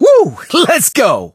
brock_start_vo_01.ogg